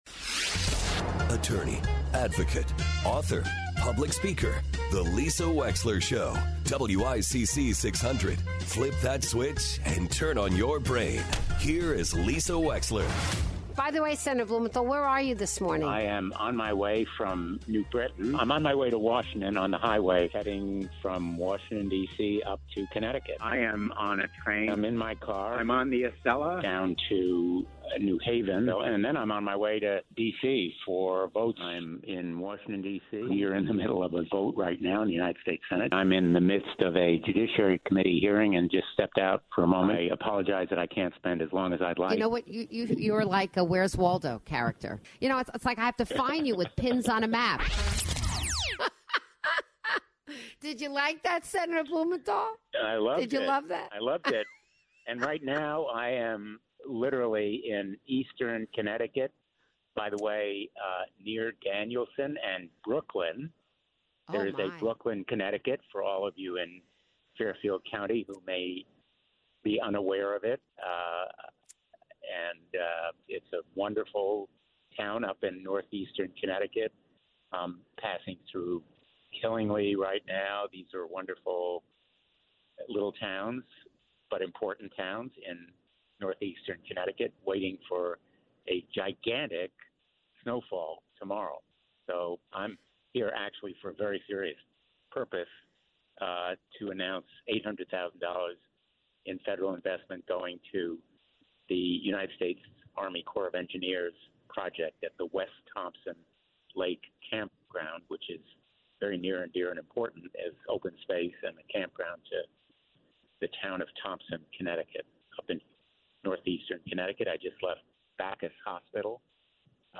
chats with the ever mobile Senator Richard Blumenthal.